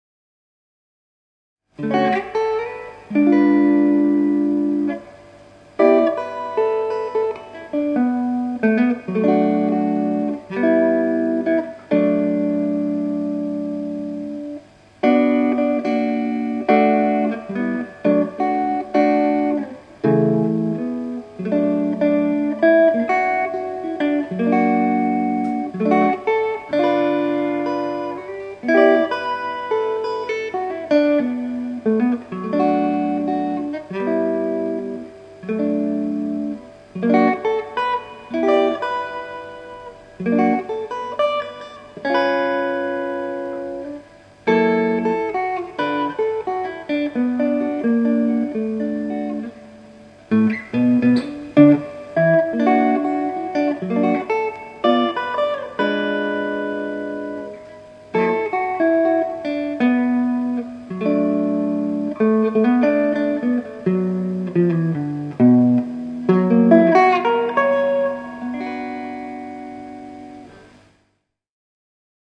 Stratocaster1954（Fender Japan 40th Anniversary model）
今日は年末スペシャルということでギター演奏を公開する。